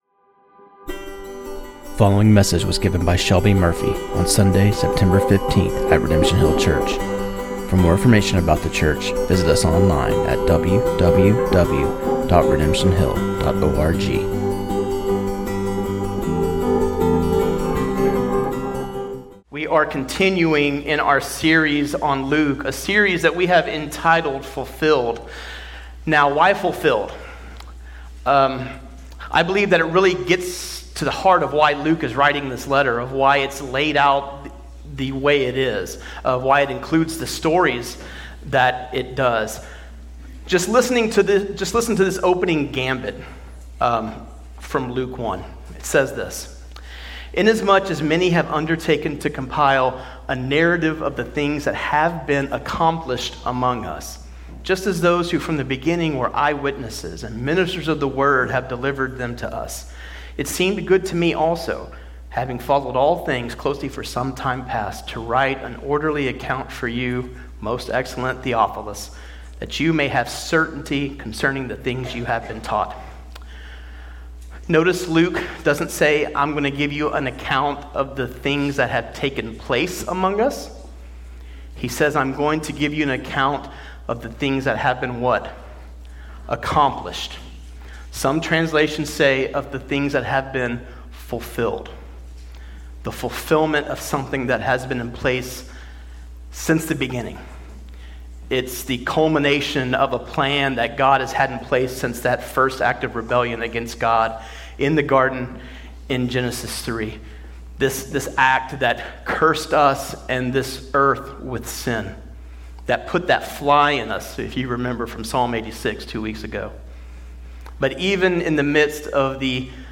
This sermon on Luke 7:11-17 was preached